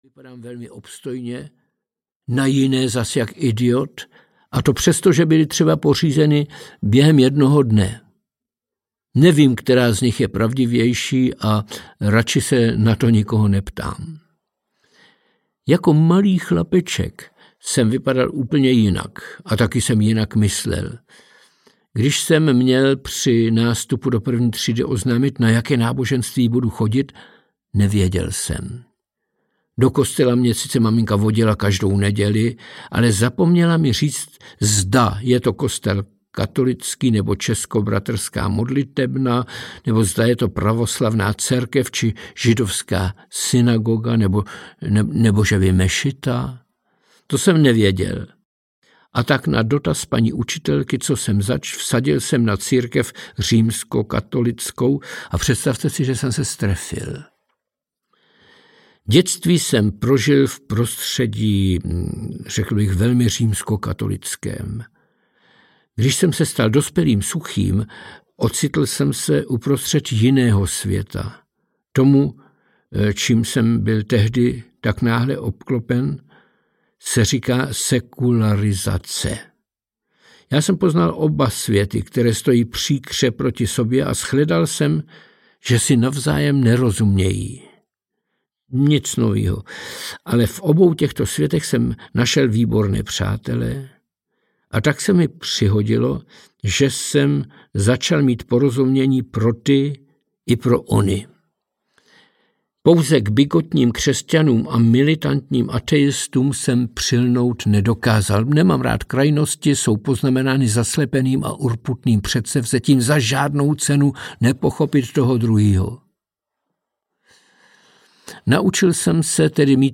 Klaun si povídá s Bohem audiokniha
Ukázka z knihy
Kniha po vydání vyvolala mimořádný ohlas a nyní ji Jiří Suchý osobně namluvil jako audioknihu.
• InterpretJiří Suchý